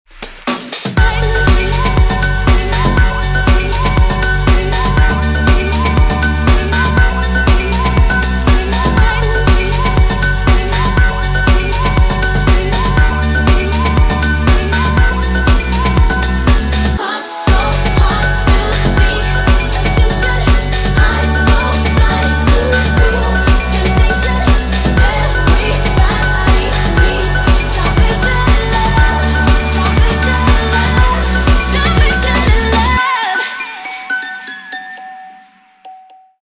• Качество: 320, Stereo
женский вокал
Electronic
EDM
progressive house